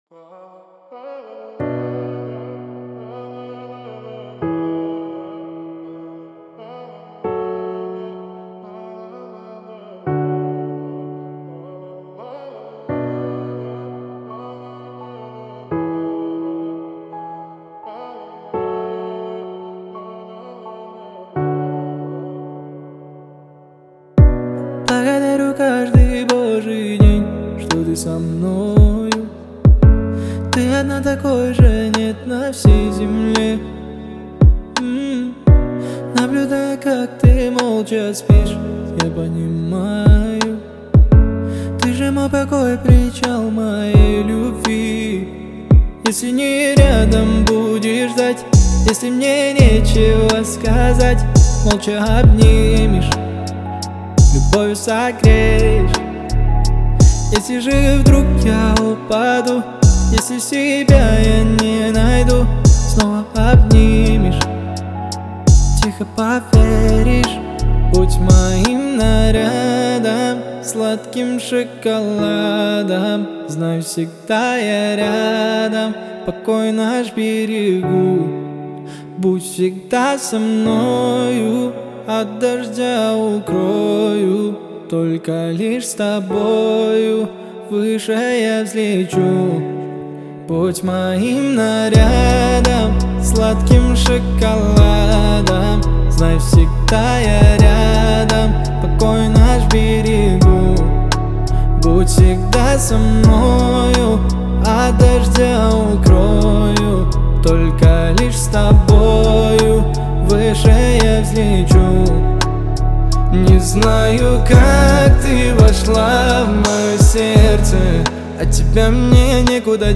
Популярная музыка Новая музыка 2022РусскаяРусский Поп